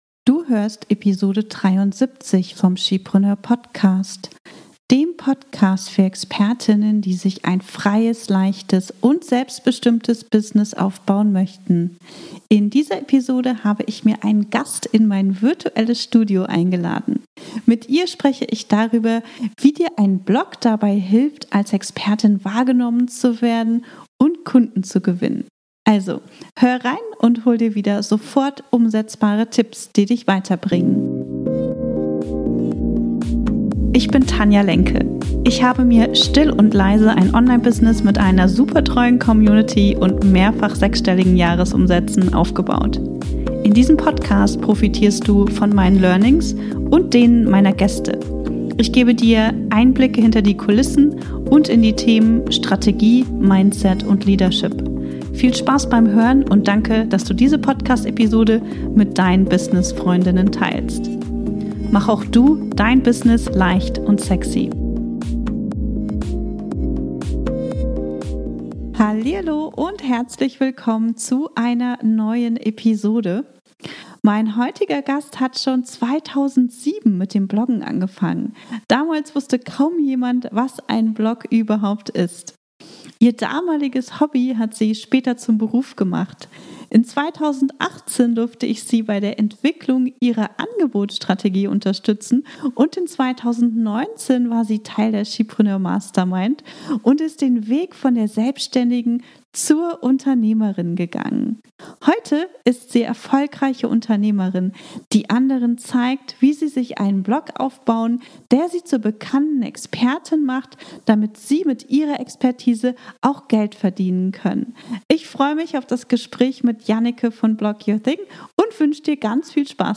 073 - Wie dir ein Blog dabei hilft, als Expertin wahrgenommen zu werden - Interview